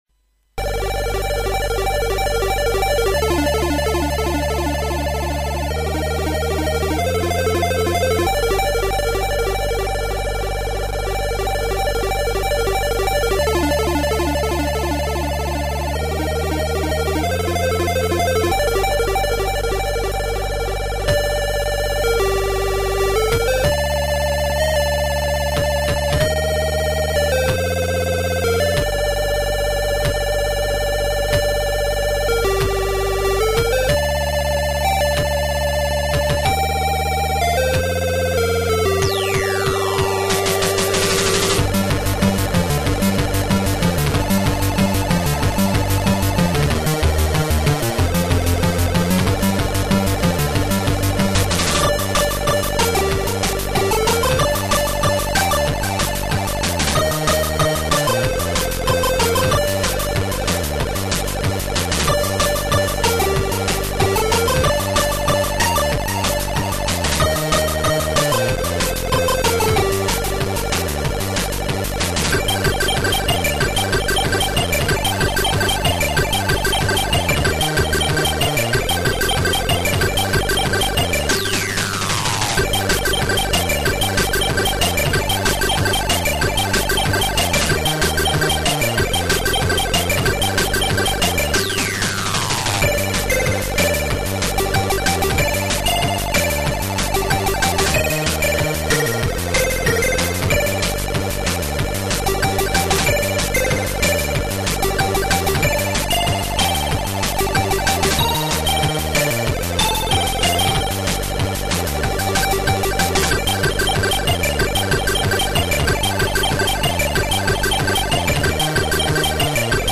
All sounds recorded using line-in of PC.
Sound Example 8 (Emulator version 16.1 2 channel 24MHz)